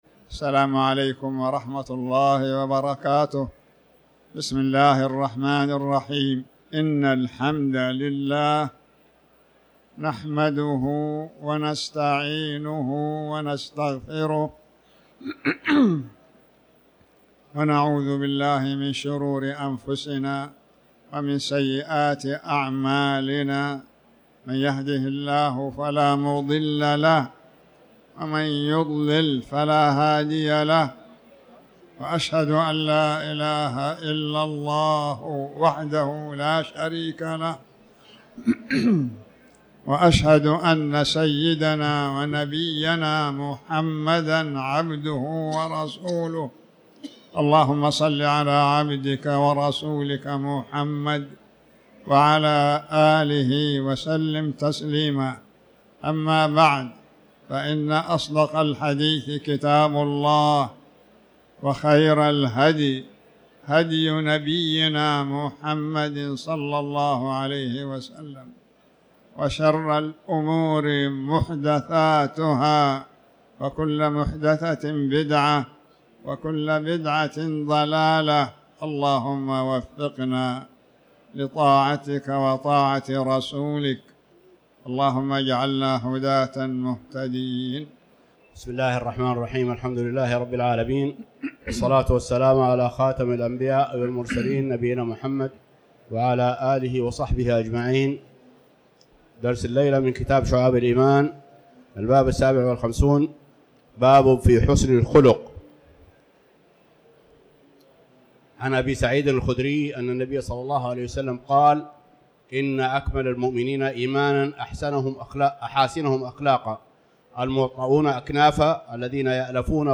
تاريخ النشر ٢٨ جمادى الآخرة ١٤٤٠ هـ المكان: المسجد الحرام الشيخ